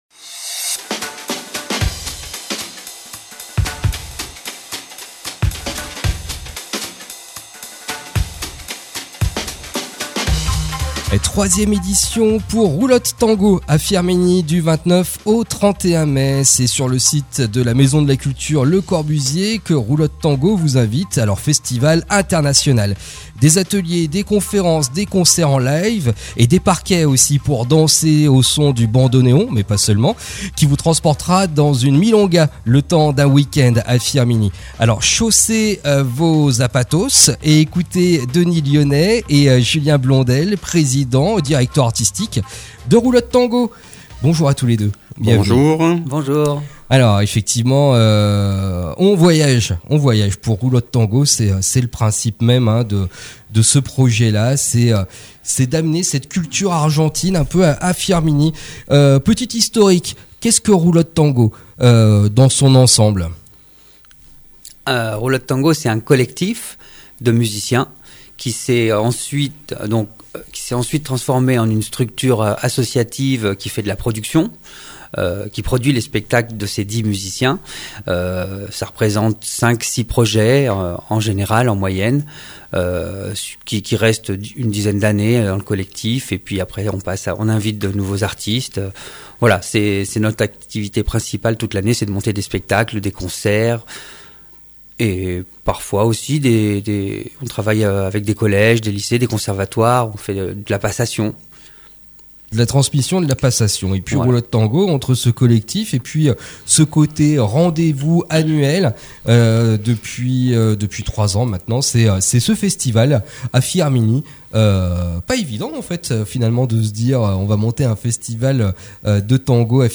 vous propose une émission spéciale